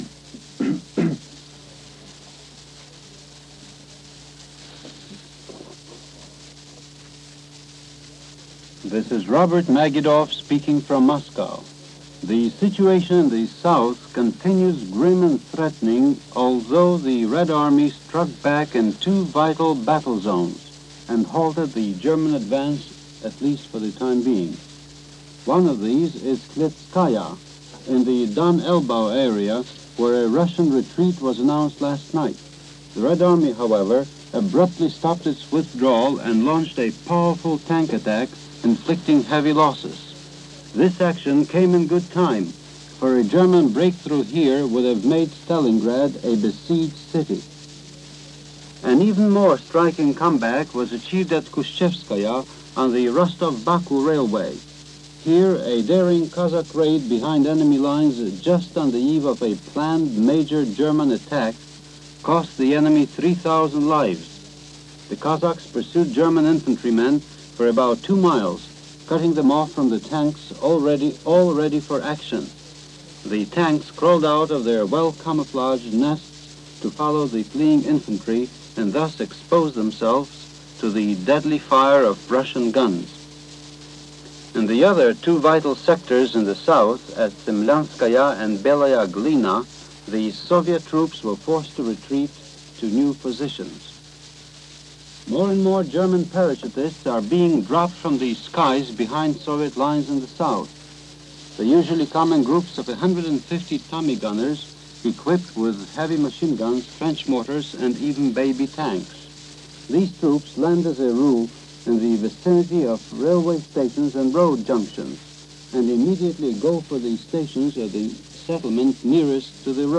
August 5, 1942 - The Eastern Front - Grim With Glimmers - A Desert Sitzkrieg - Gas Rationing At Home - News for this day in 1942.